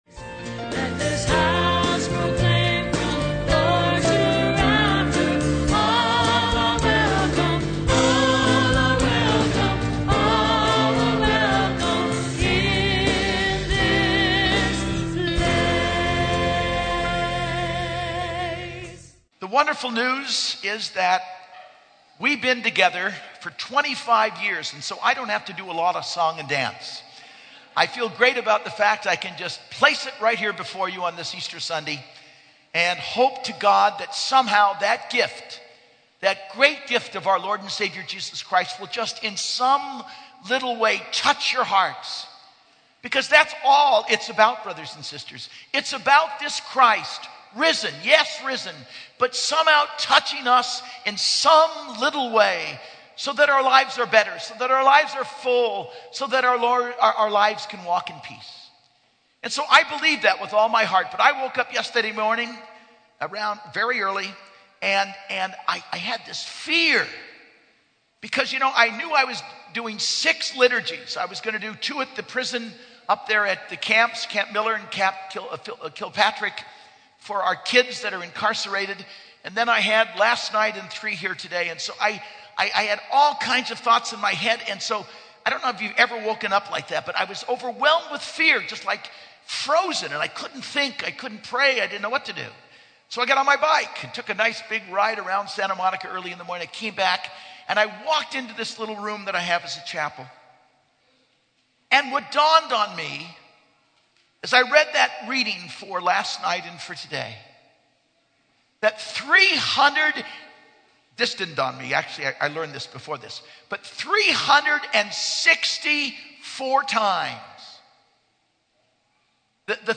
Homily - 4/8/12 - Easter Sunday